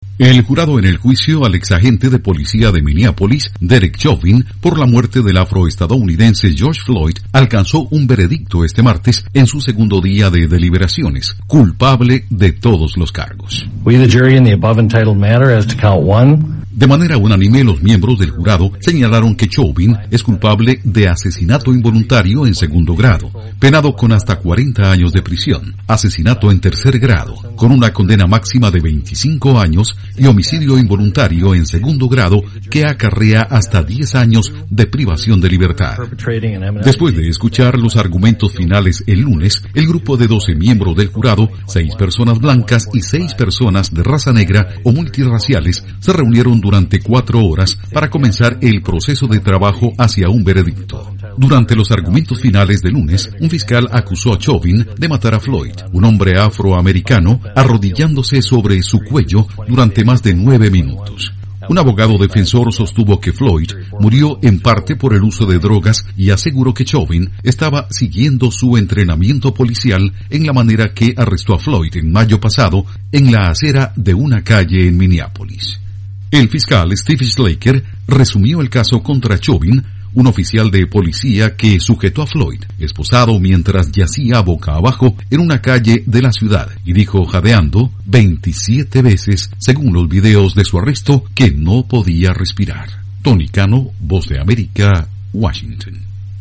El Jurado alcanza un veredicto: Chauvin es culpable de la muerte de George Floyd. Informa desde la Voz de América en Washington